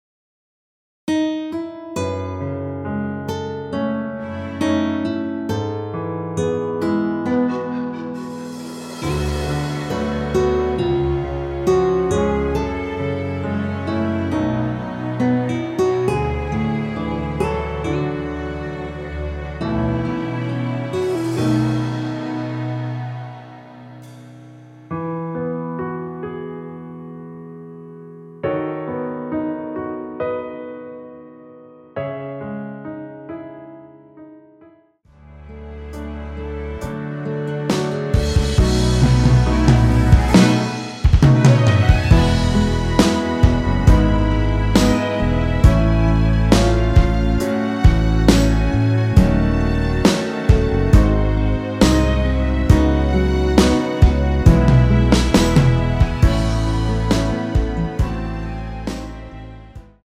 원키에서(-1)내린MR입니다.
◈ 곡명 옆 (-1)은 반음 내림, (+1)은 반음 올림 입니다.
앞부분30초, 뒷부분30초씩 편집해서 올려 드리고 있습니다.